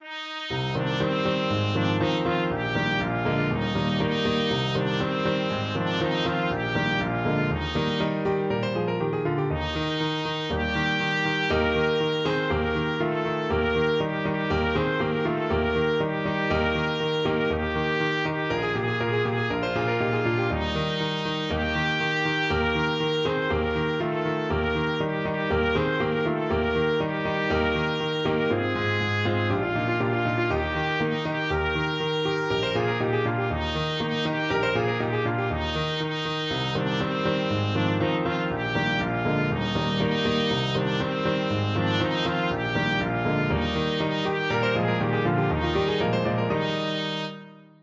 Trumpet version
Allegro (View more music marked Allegro)
2/4 (View more 2/4 Music)
C5-C6
Classical (View more Classical Trumpet Music)